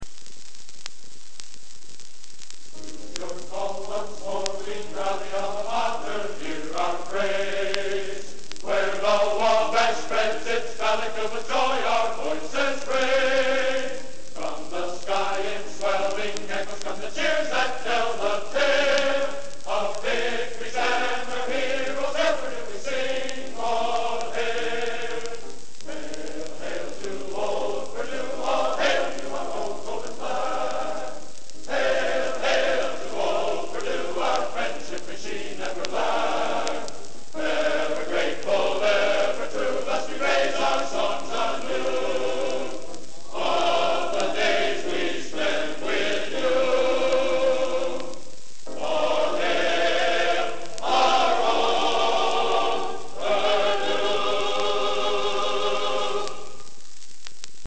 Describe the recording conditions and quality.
Genre: Collegiate | Type: Studio Recording